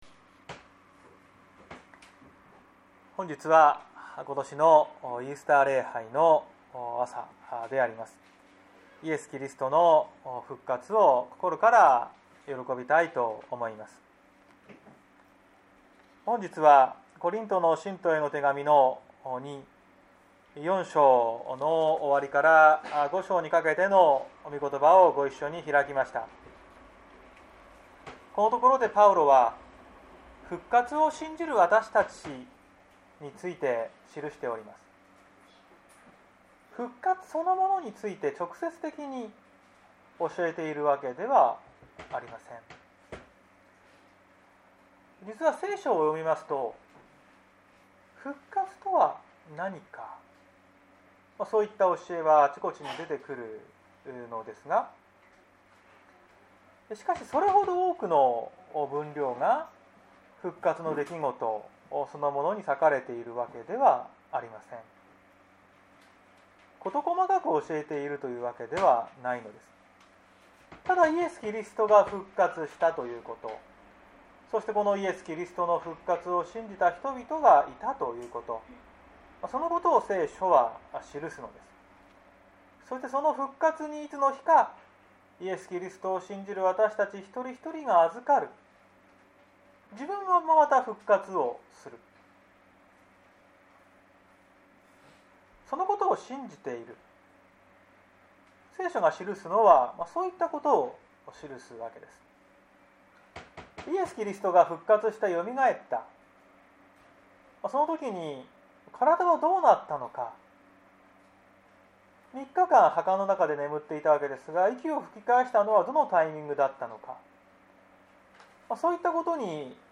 2021年04月04日朝の礼拝「復活の信仰に生きる」綱島教会
綱島教会。説教アーカイブ。